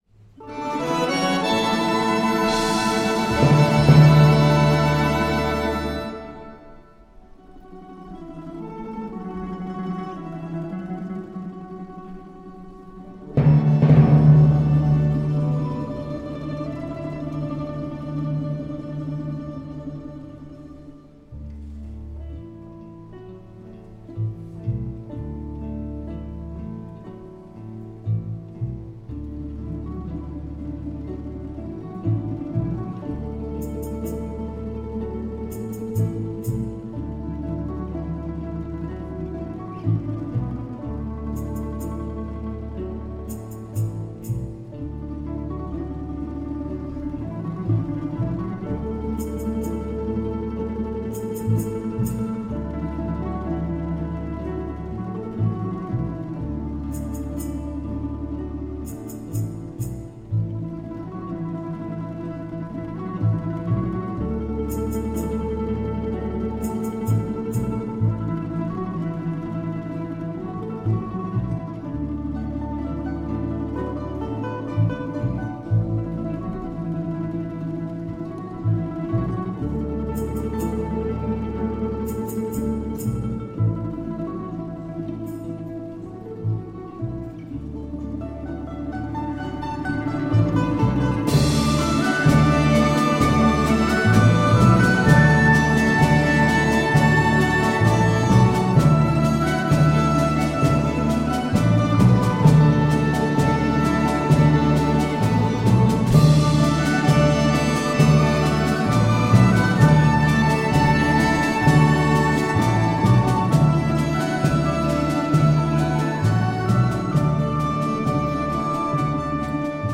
глубокая сюита